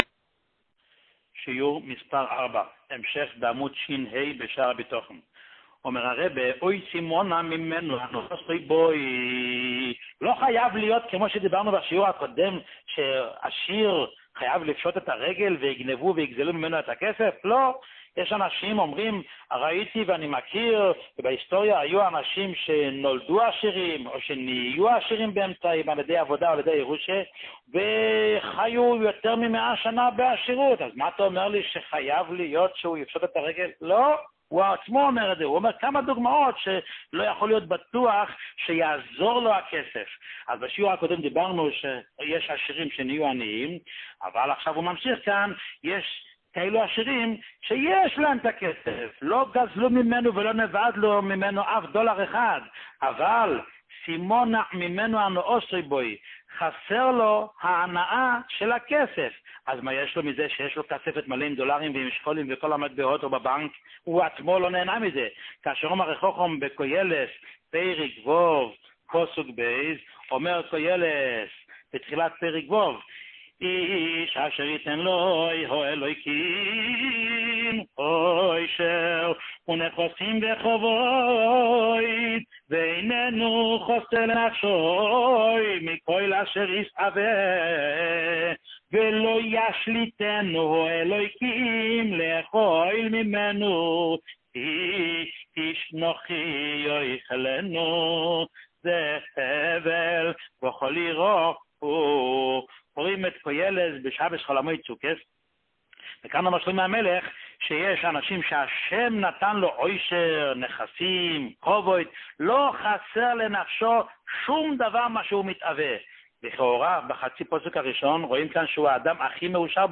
שיעורים מיוחדים
שיעור מספר 4